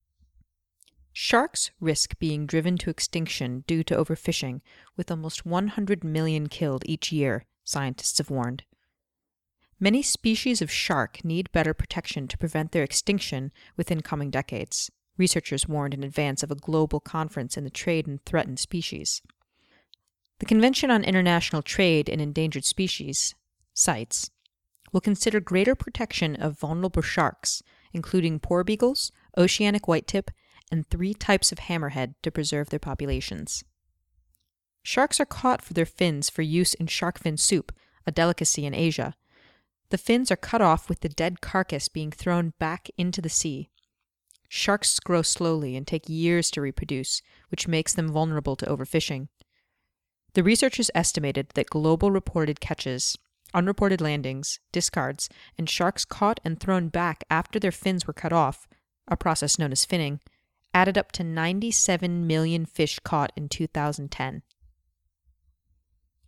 Mezzo-soprano, character actor, audiobooks, games, commercials
Sprechprobe: Industrie (Muttersprache):